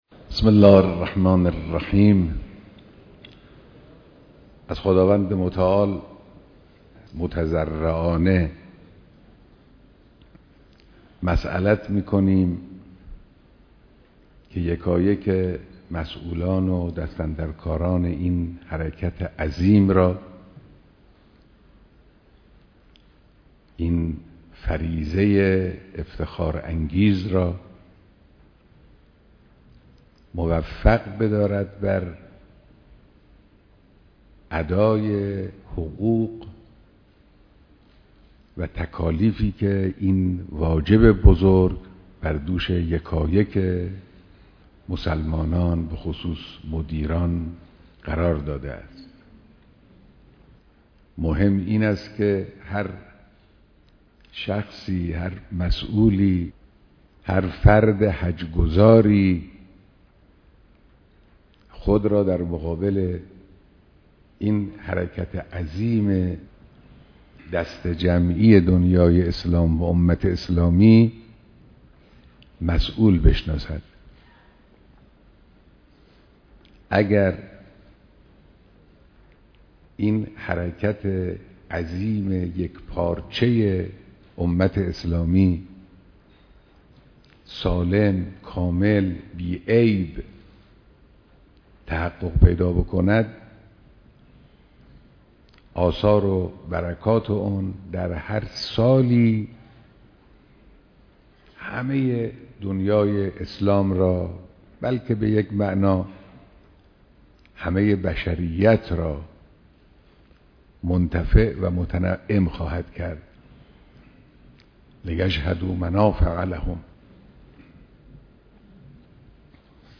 بيانات در ديدار كارگزاران حج‌